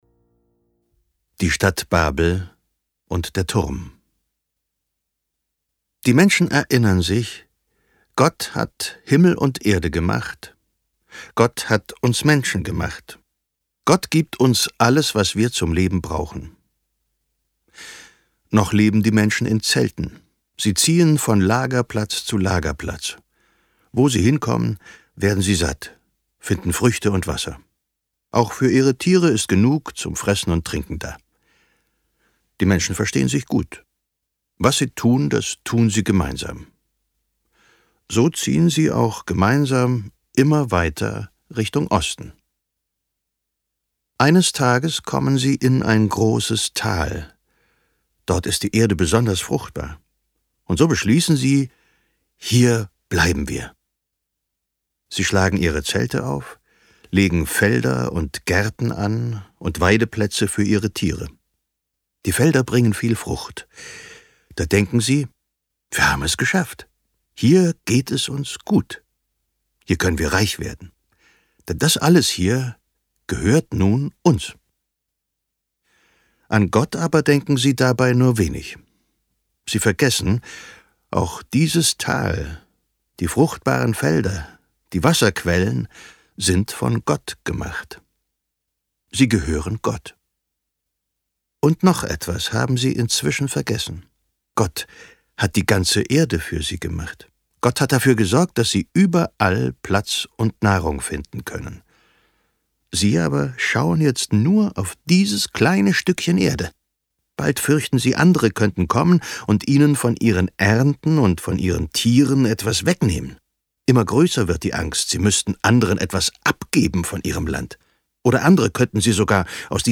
Noahs Arche und vier weitere Geschichten aus der Bibel. Gelesen von Katharina Thalbach und Ulrich Noethen
Ulrich Noethen, Katharina Thalbach (Sprecher)
Mit Kinderbibel-Liedern zum Nachdenken und Mitmachen.
Sie lesen die Texte der beliebten Kinderbibelgeschichten, die bereits als Mini-Bilderbuch und als Kamishibai-Bildfolge erschienen sind.